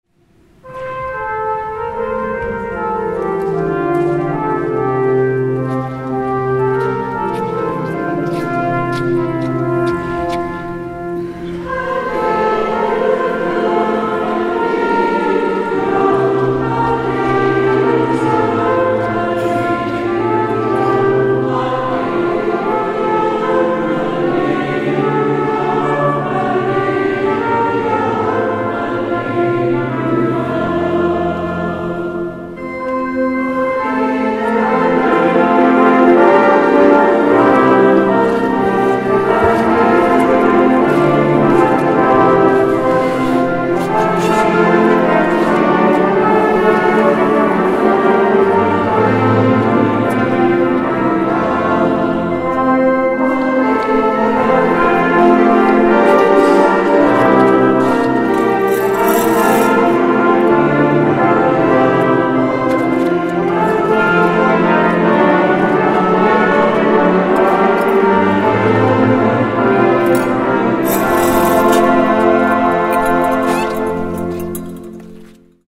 Gattung: Messe